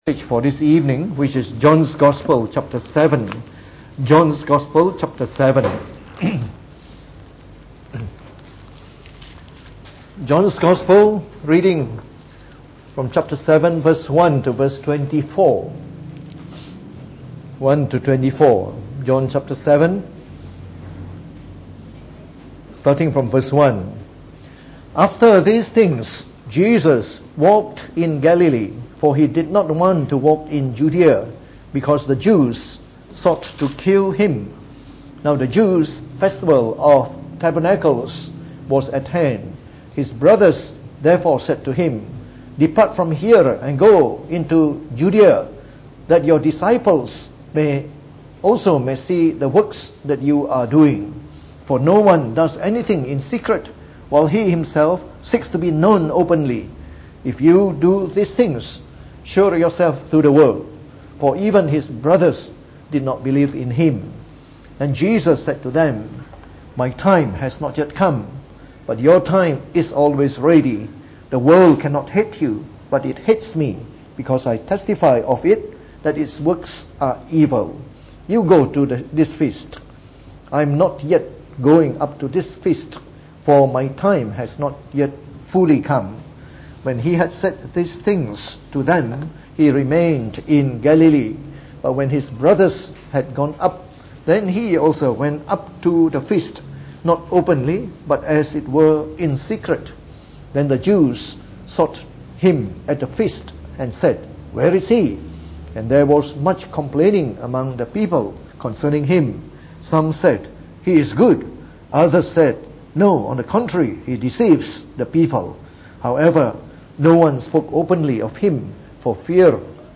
Preached on the 11th November 2018. From our series on the Gospel of John delivered in the Evening Service